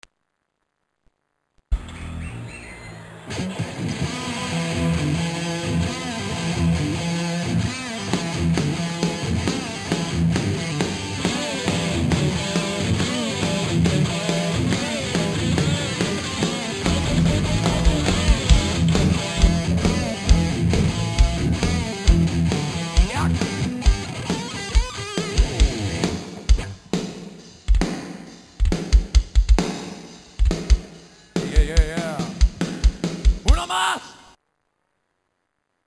Jam